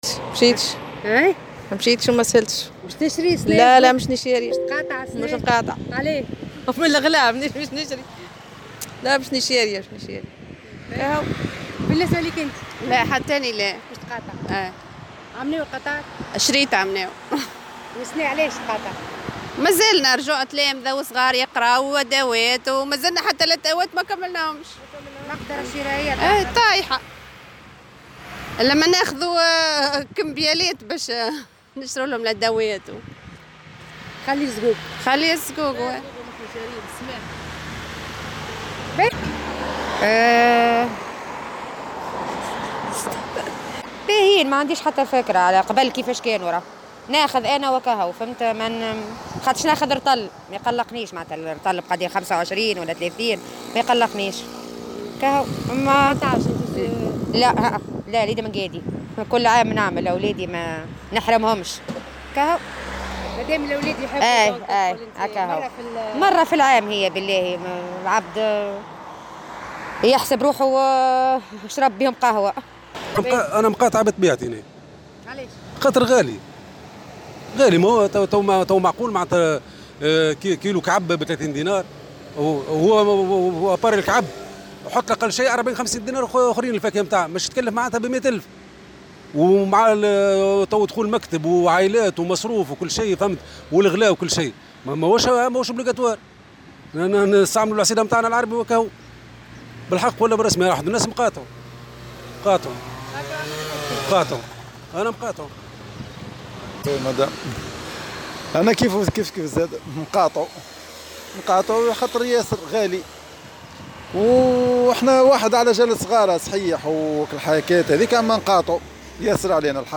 وقالوا في تصريحات لـ"الجوهرة أف أم" إنهم أصبحوا خلال السنوات الأخيرة يتخلّون عن هذه الأكلة التقليدية نظرا لضعف المقدرة الشرائية وعدم قدرتهم على مجابهة المصاريف الضرورية الأخرى من مستلزمات العودة المدرسية ونفقات الحياة الأساسية.